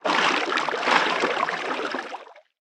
Sfx_creature_lillypaddler_posetoswim_01.ogg